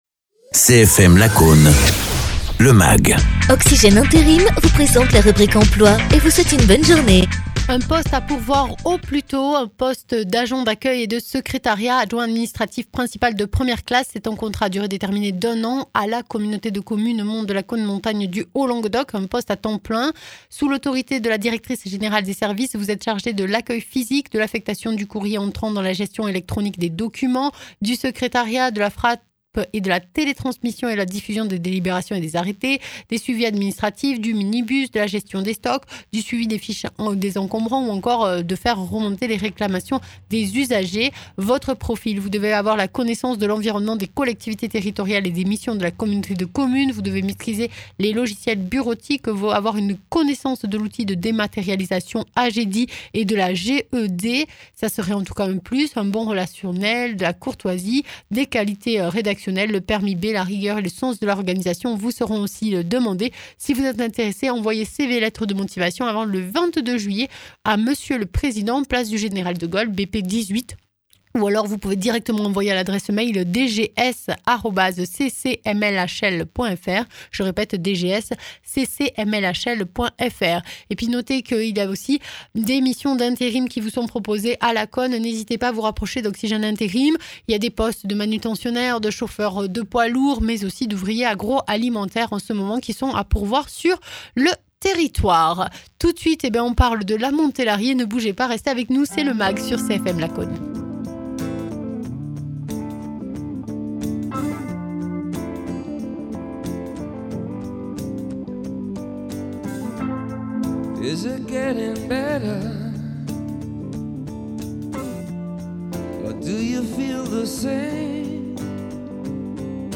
Pierre Escande, maire de Lamontélarié (Tarn)